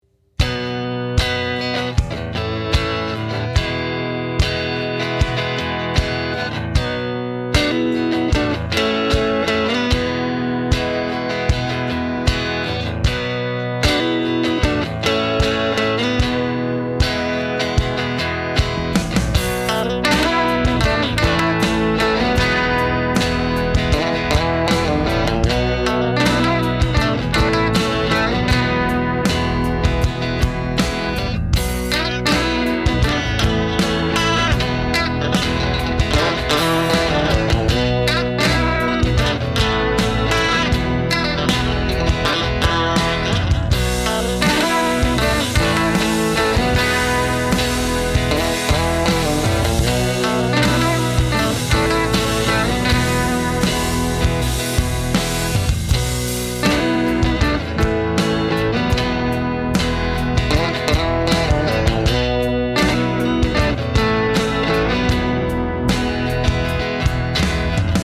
Taking a nod from the vintage practice amps of yesteryear, the Lil’ P is a fine neighbor friendly pedal platformer with it’s own gritty personality.
Lil-P-Steve-Miller-band-style-Rock-FINAL-.mp3